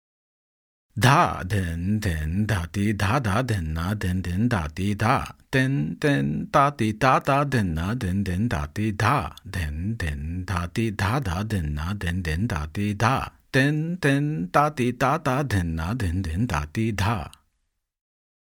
Spoken 2 times: